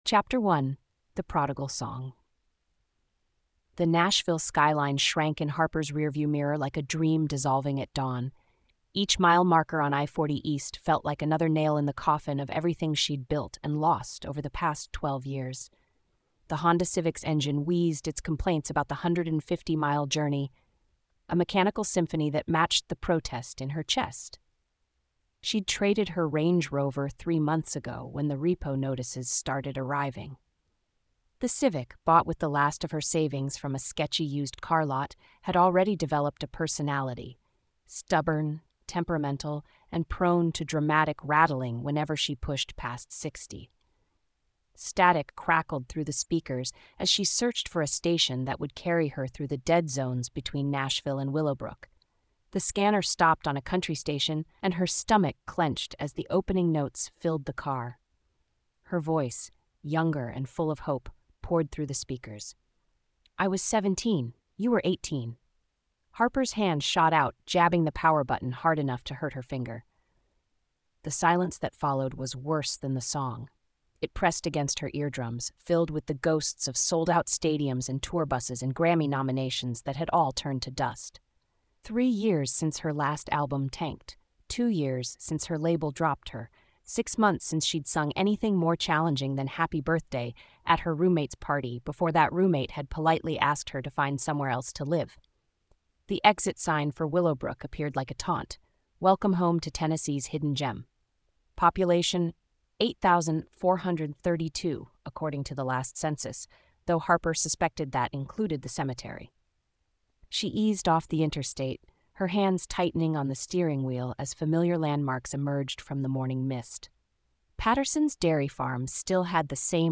Listen to the first chapter narrated with professional voice synthesis